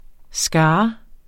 Udtale [ ˈsgɑː ]